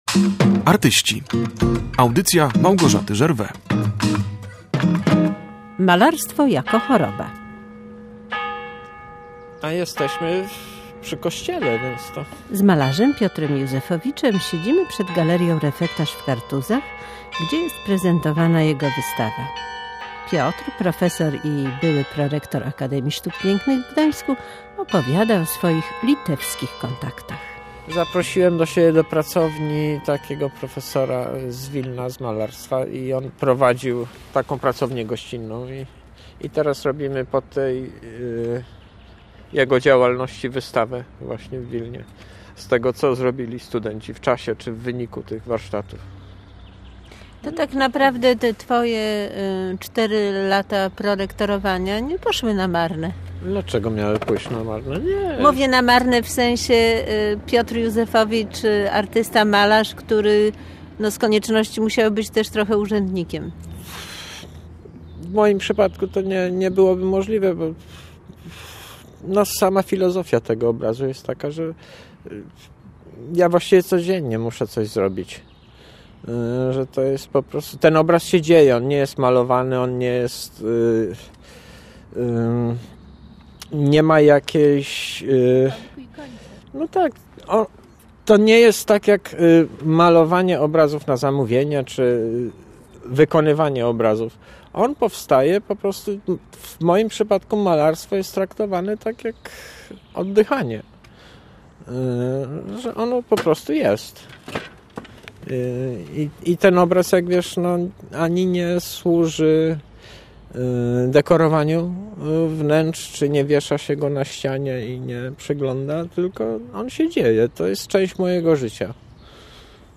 Rozmowy toczy się w cieniu kartuskiego refektarza i w rytmie dzwonów z wieży kościelnej.
Tagi: dokument reportaż